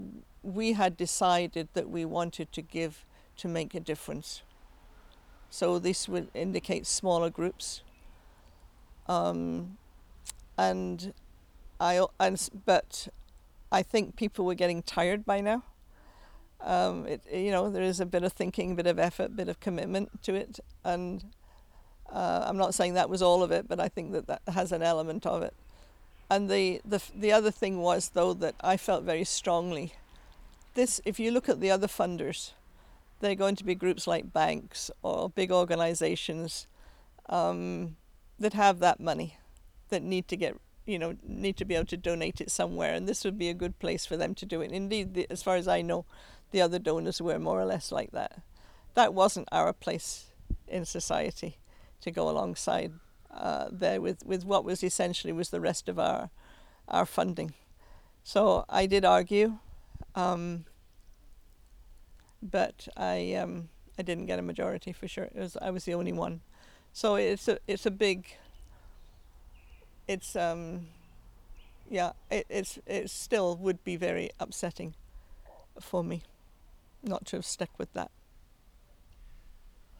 when we recorded an audio interview in the backyard of her home.